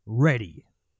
Voices / Male / Ready 1.wav
Ready 1.wav